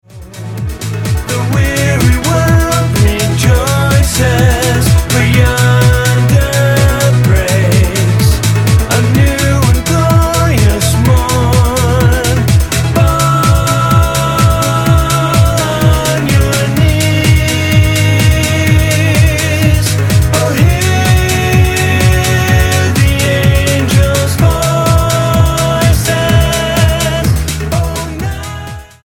Acoustic duo
Guitar And Violin
Style: Pop